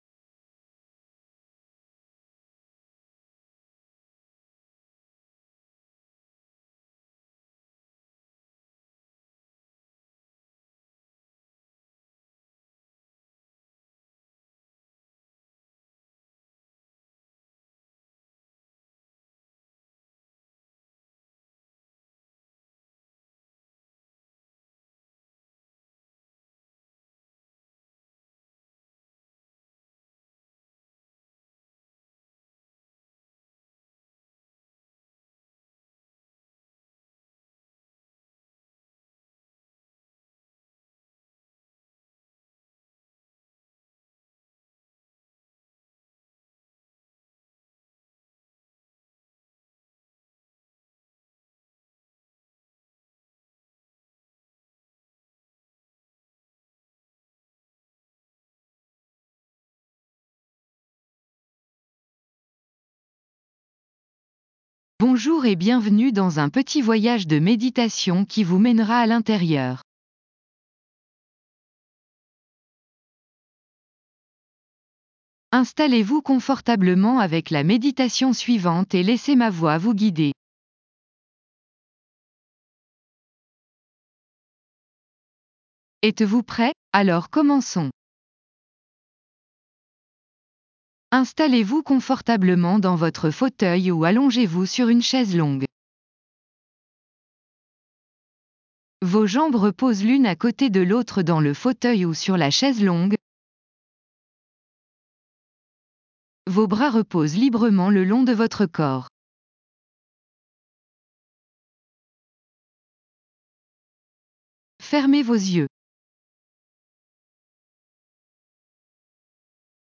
Restez concentré sur ces passages pendant que vous laissez la musique vous submerger.